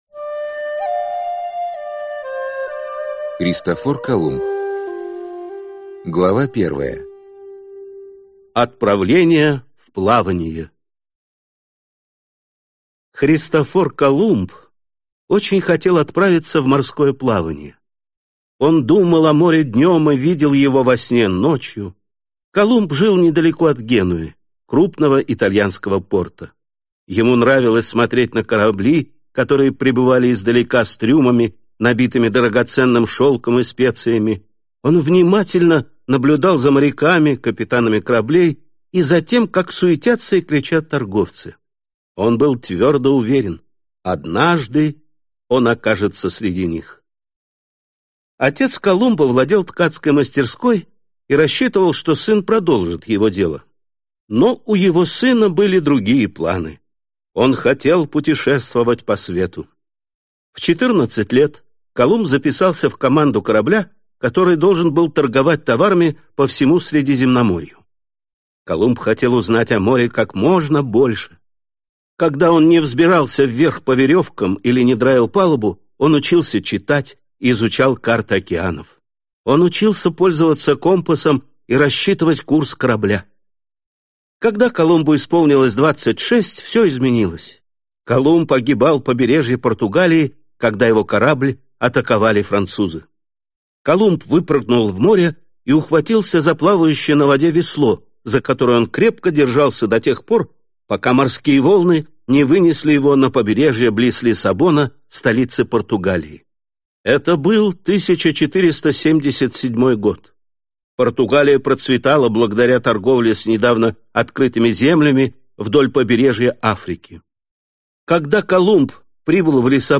Аудиокнига Курс 1. Христофор Колумб. Урок 4: Опередить всех, следуя на Запад | Библиотека аудиокниг